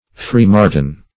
Search Result for " free-martin" : The Collaborative International Dictionary of English v.0.48: Free-martin \Free"-mar`tin\, n. (Zool.) An imperfect female calf, twinborn with a male, which is sterile as a result of exposure to masculinizing hormones.